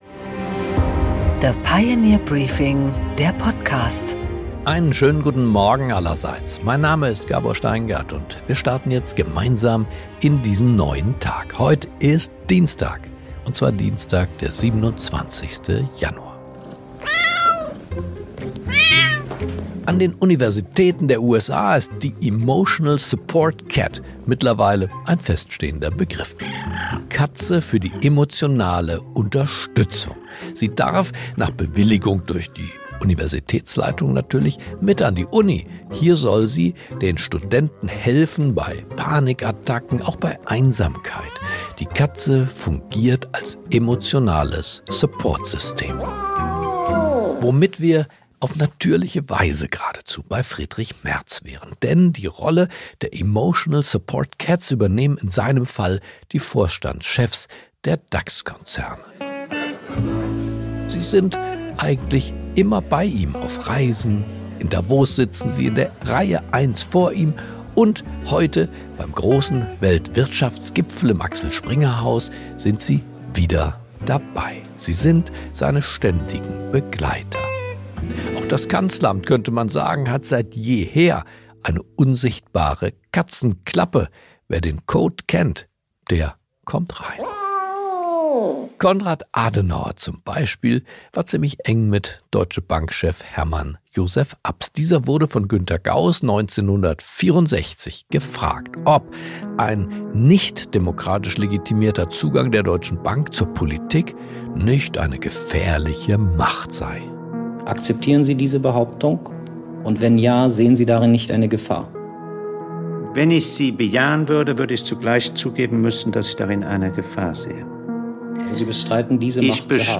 Gabor Steingart präsentiert das Pioneer Briefing.
Im Gespräch: Prof. Veronika Grimm, Ökonomin und neue Pioneer‑Mitherausgeberin, ordnet die Lage der deutschen Wirtschaft ein: kein reales Wachstum seit Jahren, Investitionsschwäche, strukturelle Bremsen.